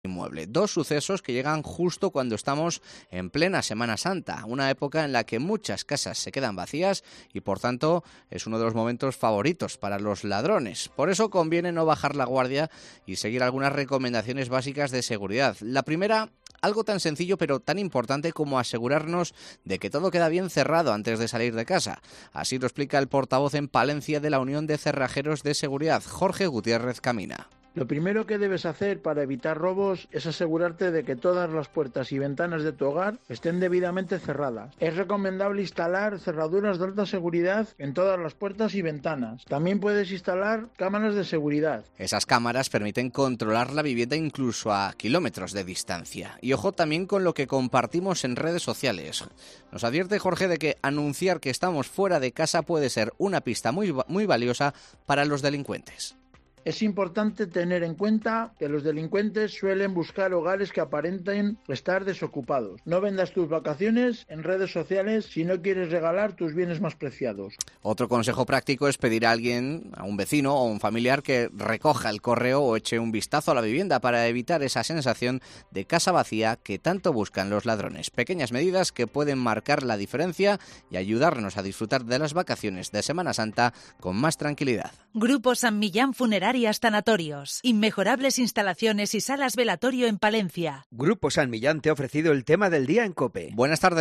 Un profesional dedicado a la seguridad detalla los errores más comunes que cometemos y que dejan la puerta abierta a los delincuentes durante las vacaciones